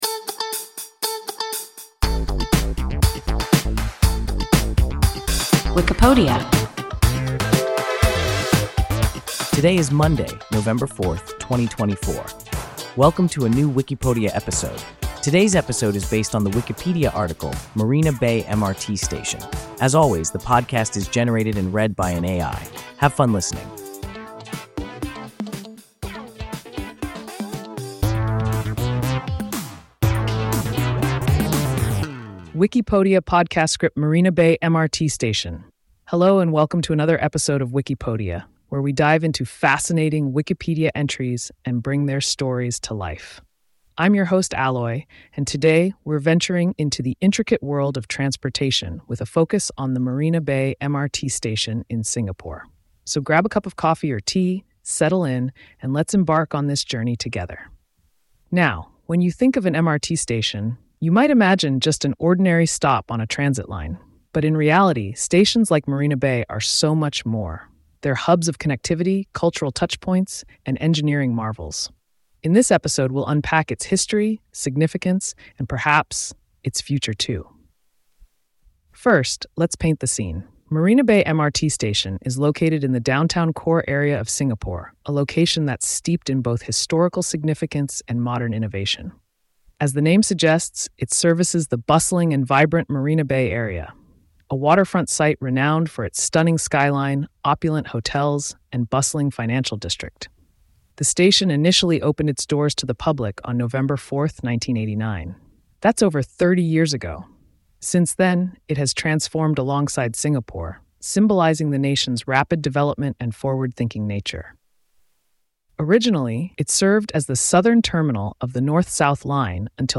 Marina Bay MRT station – WIKIPODIA – ein KI Podcast